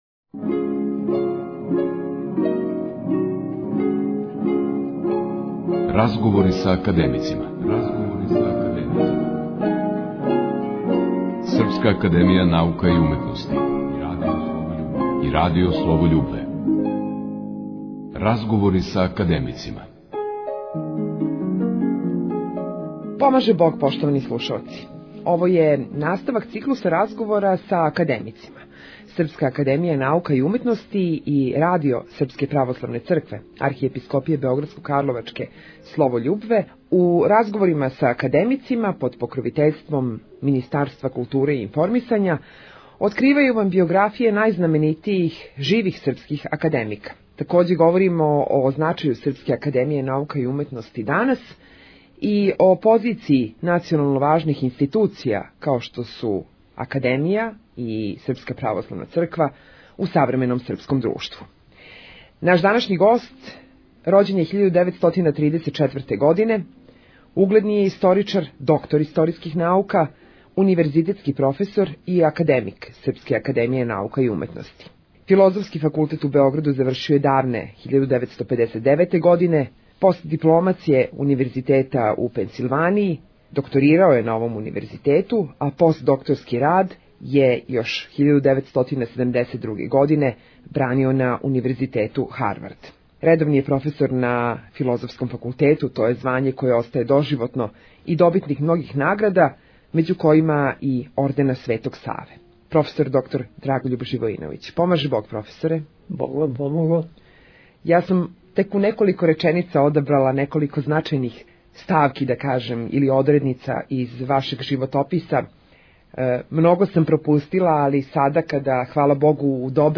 Поводом упокојења академика др Драгољуба Живојиновића, емитујемо емисију "Разговори са академицима" - последњи интервју академика Живојиновића у српским медијима